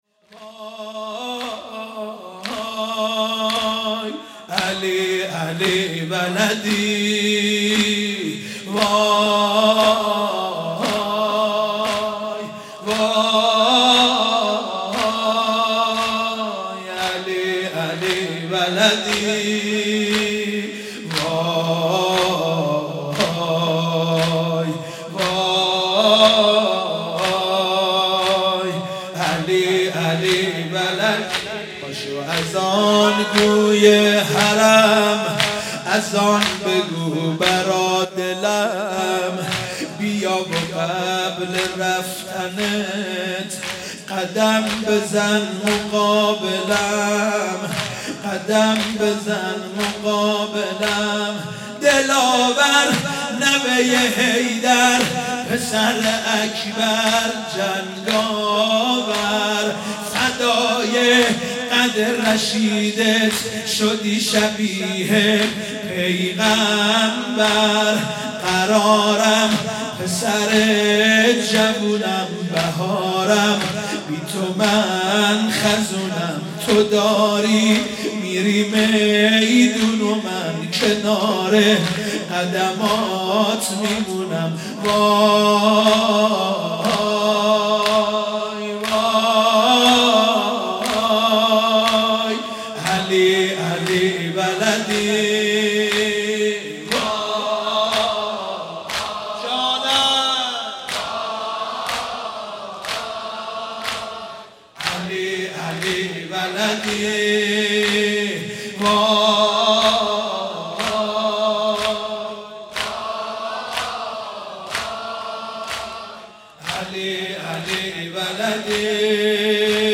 (زمینه)